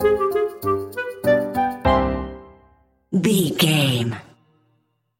Aeolian/Minor
flute
oboe
strings
orchestra
cello
double bass
percussion
silly
goofy
cheerful
perky
Light hearted
quirky